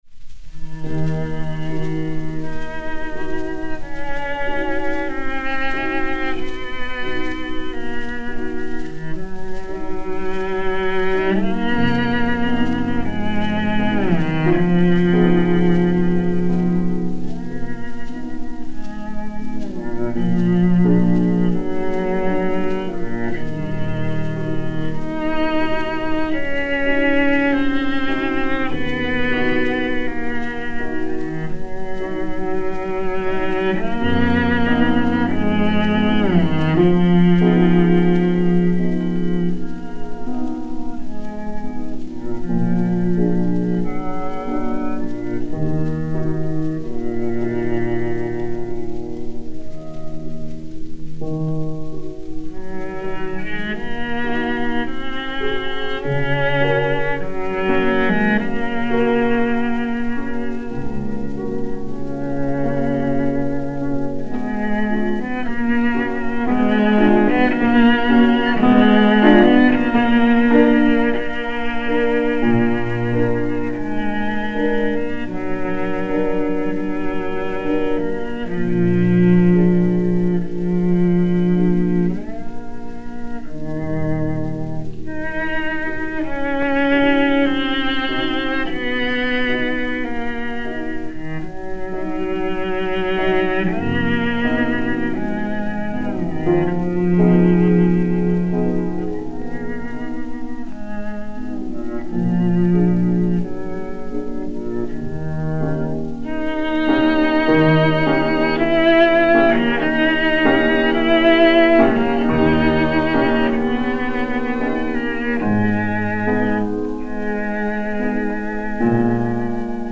violoncelle
piano